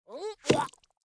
Npc Catspit Sound Effect
npc-catspit-2.mp3